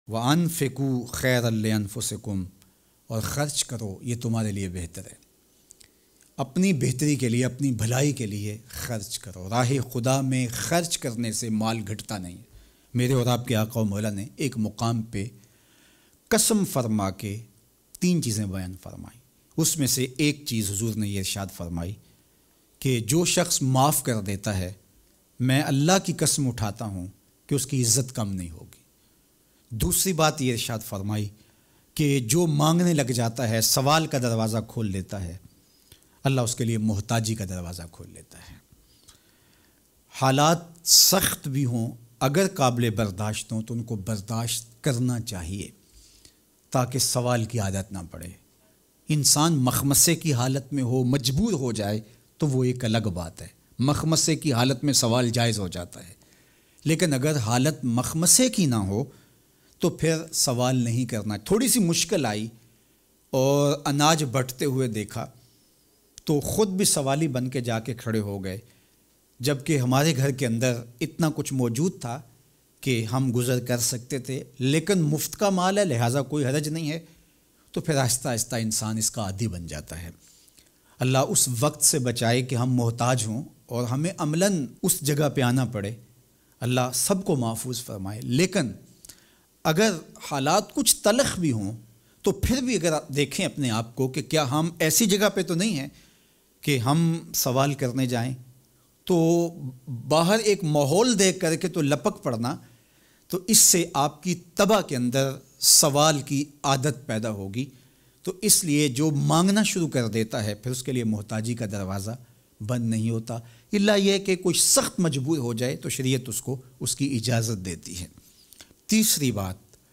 New-Bayan-about-LockDown.mp3